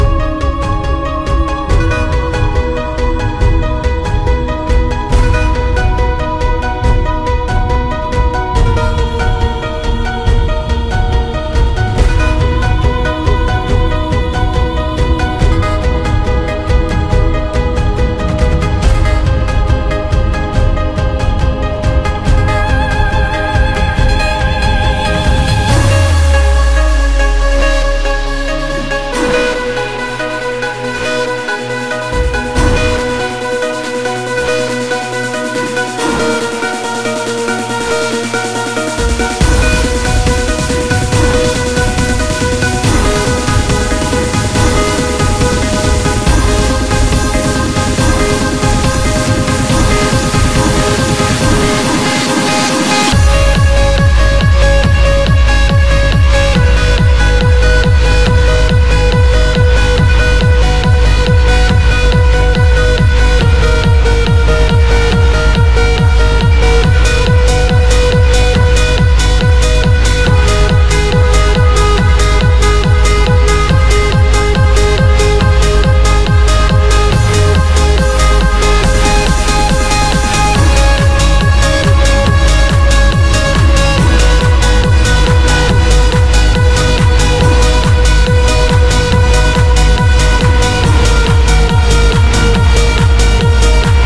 Además de la mezcla original